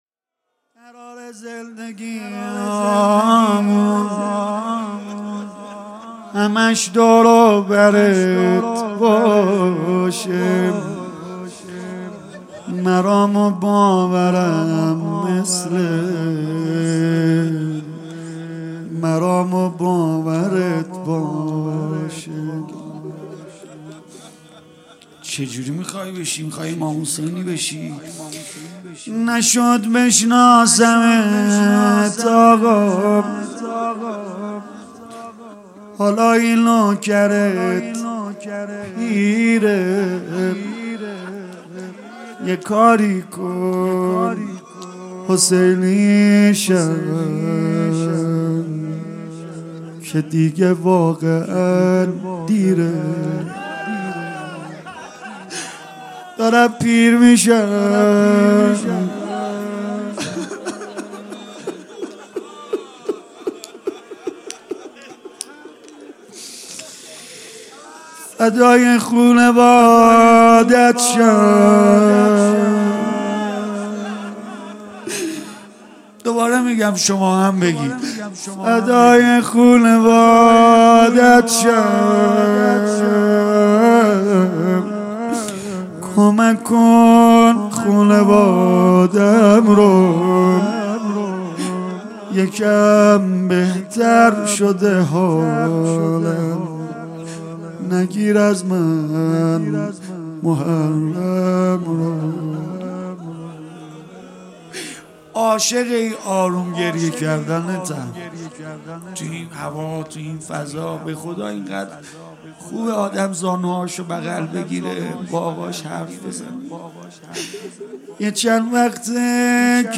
سید رضا نریمانی شب دوم محرم 99 هیات فداییان حسین علیه السلام اصفهان